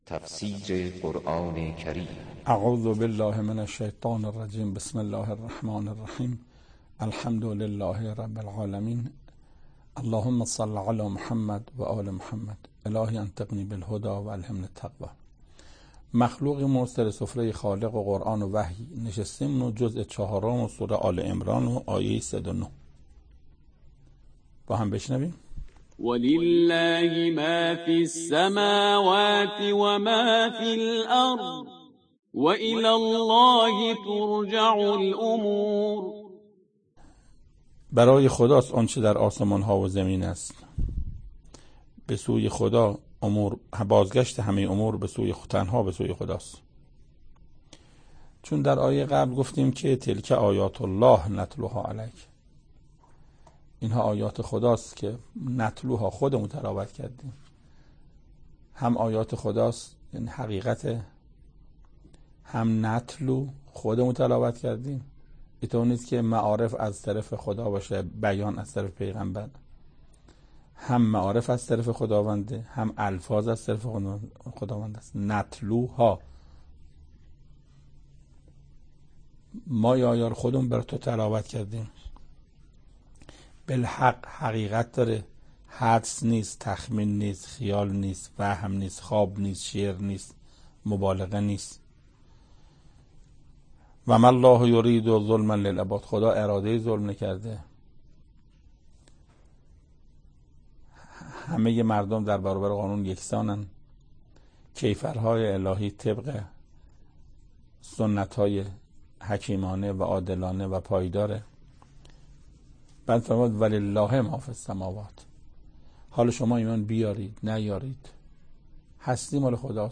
تفسیر صد و نهمین آیه از سوره مبارکه آل عمران توسط حجت الاسلام استاد محسن قرائتی به مدت 7 دقیقه